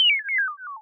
computer_b.wav